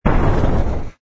engine_br_fighter_kill.wav